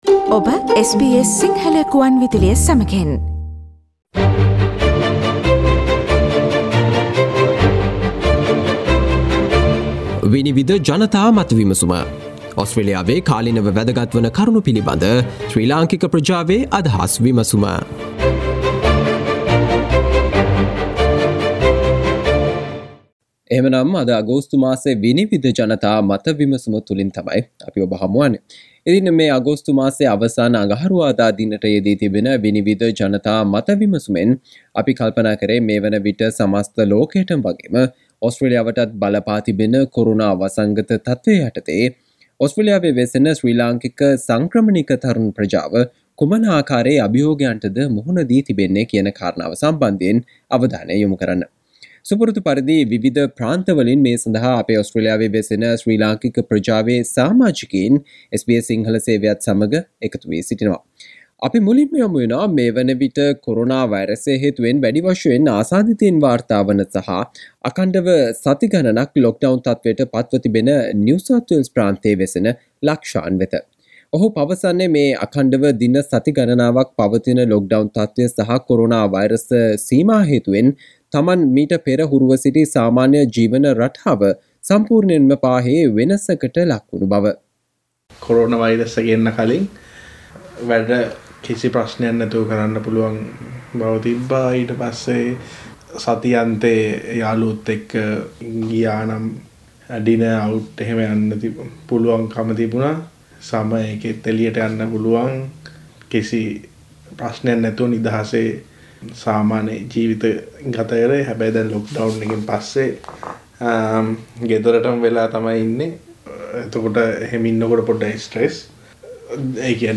'ලේසි නෑ මේ ජීවිතේ' - කොරෝනා කාලයේ ඇති අභියෝග ගැන ඕස්ට්‍රේලියාවේ ශ්‍රී ලාංකික තරුණ පිරිස් කතා කරයි: 'විනිවිද' ජනතා මත විමසුම
කොරෝනා තත්වය හමුවේ ඕස්ට්‍රේලියාවේ දැනට සිටින සංක්‍රමණික ශ්‍රී ලංකික තරුණ පිරිස පිඩාවට පත්ව ඇති කාරනා පිලිබඳ අදහස් වලට සවන් දෙන්න මෙම අගෝස්තු මාසයේ SBS සිංහල ගුවන් විදුලියේ 'විනිවිද' ජනතා මත විමසුම තුලින්